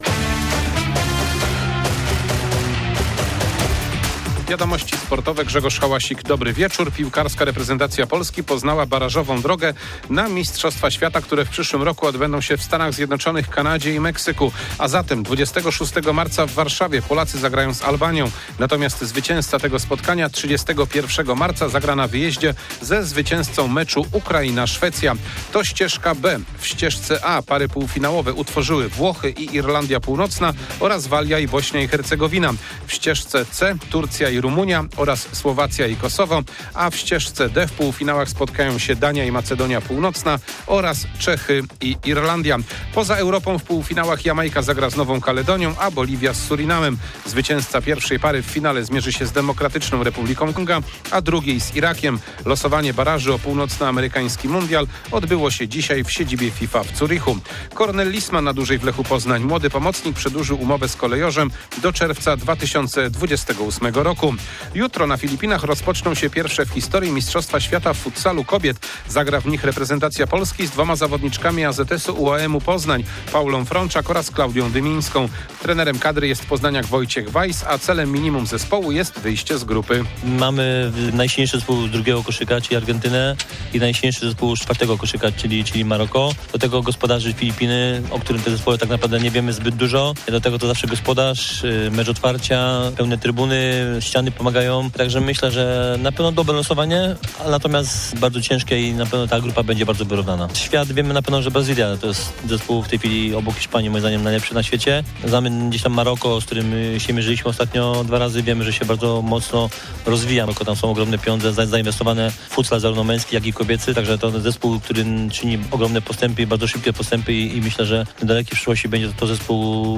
20.11.2025 SERWIS SPORTOWY GODZ. 19:05
W czwartkowym serwisie sportowym o reprezentacji Polski w futsalu kobiet, która rozpoczyna udział na mistrzostwach świata na Filipinach, koszykarki Enea AZS Politechniki Poznań przed meczem w Lublinie oraz gwar z sali szkoły w Plewiskach, gdzie dzieci grały w piłkę ręczną.